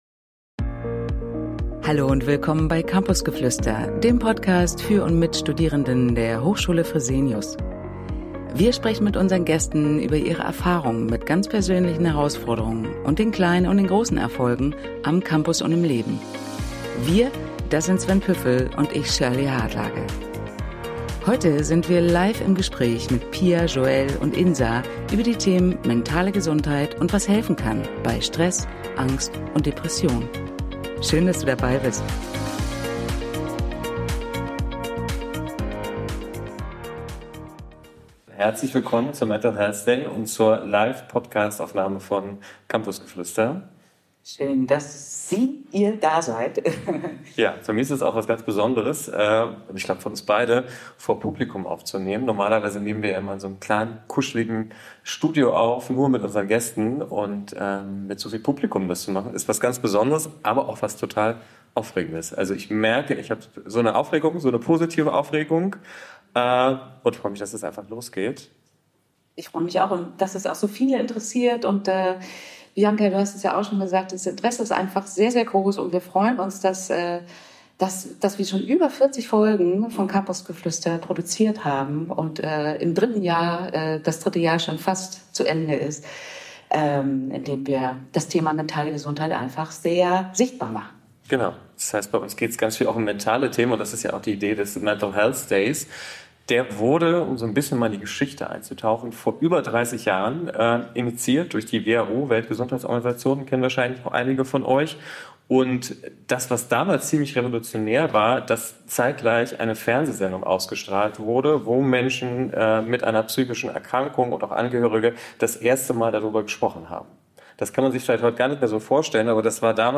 In dieser Live-Episode, aufgenommen am Mental Health Day am Campus in Hamburg, teilen drei Studierende ihre persönlichen Geschichten. Offen und ehrlich berichten sie von den Herausforderungen, denen sie sich stellen mussten, und den Strategien, die ihnen helfen, mental gesund zu bleiben.